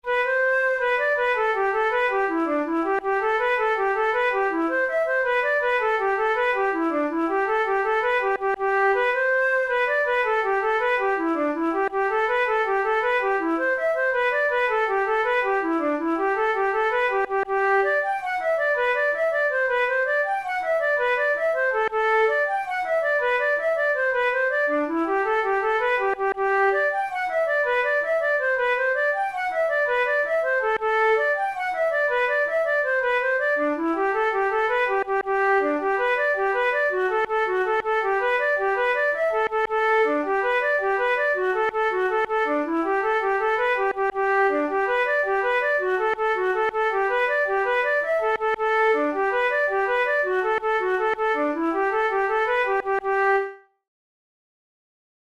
InstrumentationFlute solo
KeyG major
Time signature6/8
Tempo108 BPM
Jigs, Traditional/Folk
Traditional Irish jig